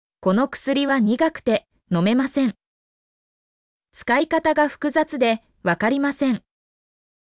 mp3-output-ttsfreedotcom-4_2u9HPex9.mp3